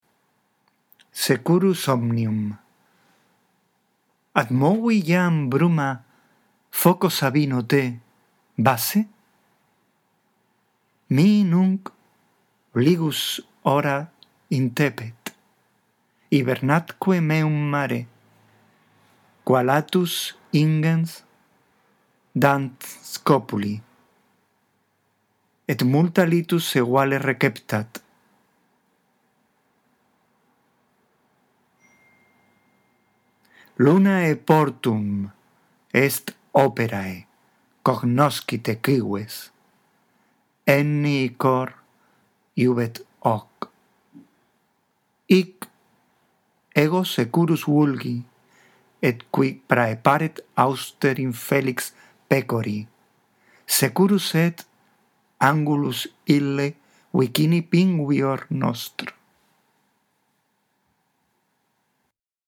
Aquí tienes una lectura que te ayudará con la pronunciación y la entonación correcta: